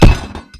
街头曲棍球 啪啪啪金属打击包 " 啪啪啪金属打击3
Sennheiser MKH416Sound Devices 664
标签： 射击 曲棍球 砰的一声 巴掌 金属 命中 冲击
声道立体声